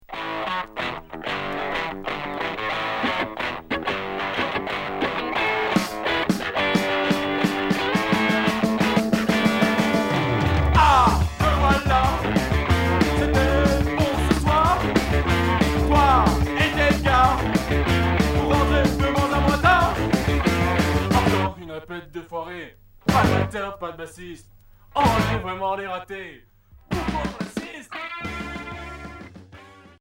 Rock punk Unique 45t retour à l'accueil